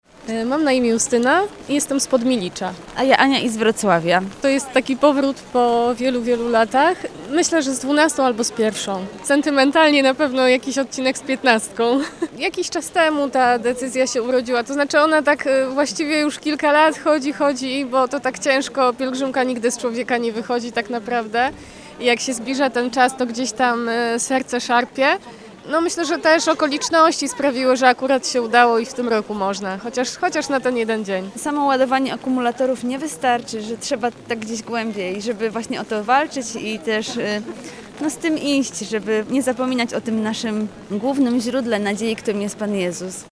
Trzeba szukać głębi – mówią uczestniczki pielgrzymki.